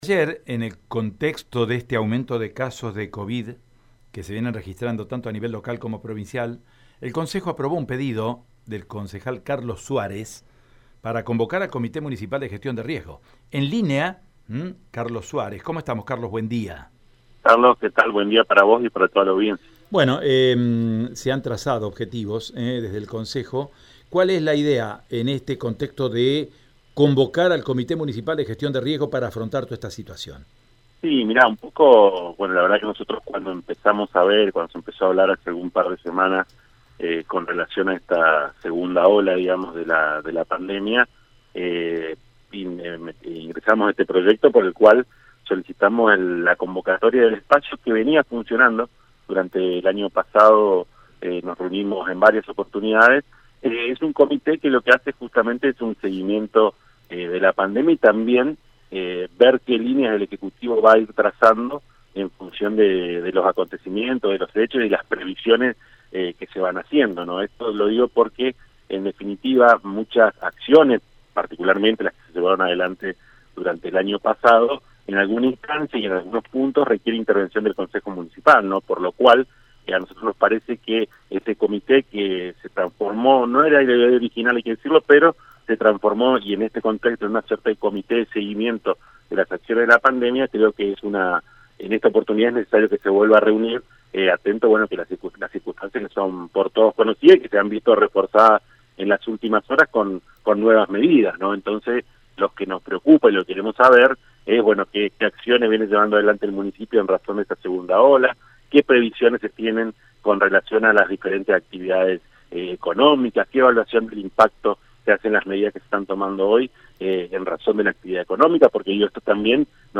En diálogo con Radio EME, Suárez explicó que “ingresamos este proyecto por el cual solicitamos la convocatoria del espacio que venía funcionando. Es un Comité que justamente lo que hace es un seguimiento de la pandemia y también ver que líneas el Ejecutivo va trazando en función de los acontecimiento”.
NOTA-Hablamos-con-el-Concejal.mp3